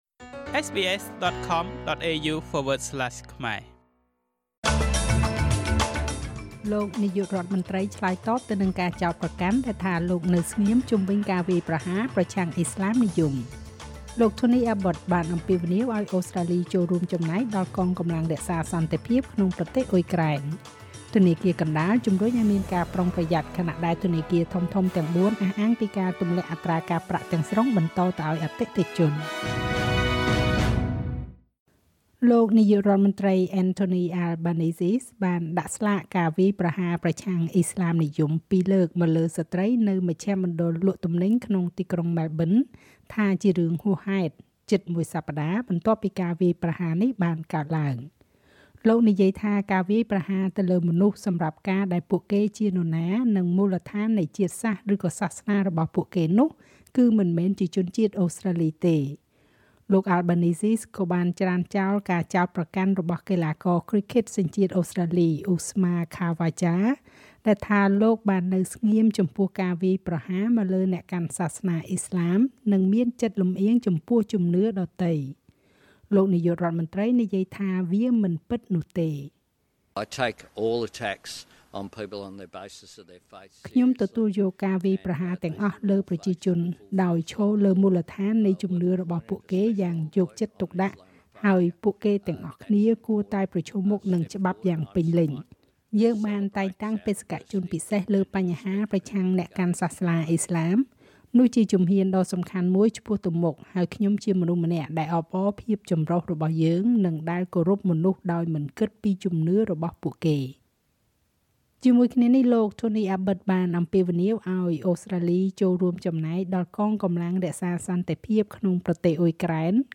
នាទីព័ត៌មានរបស់SBSខ្មែរ សម្រាប់ថ្ងៃពុធ ទី១៩ ខែកុម្ភៈ ឆ្នាំ២០២៥